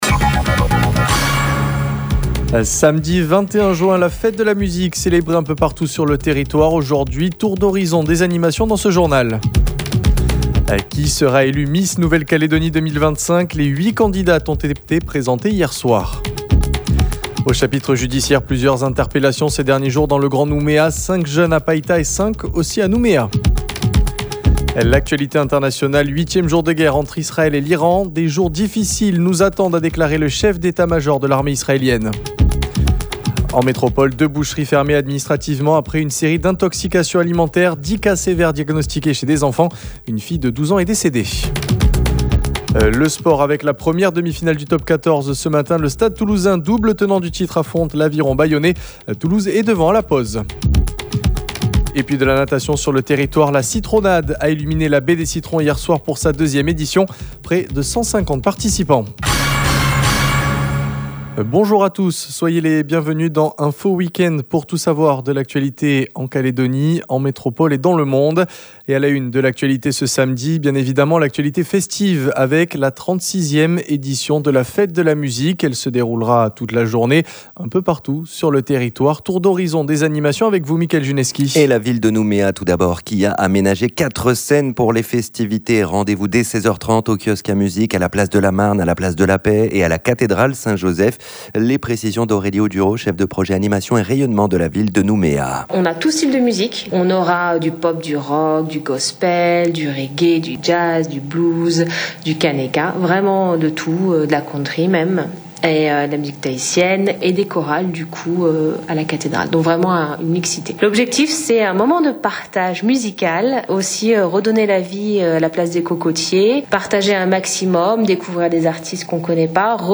Le Journal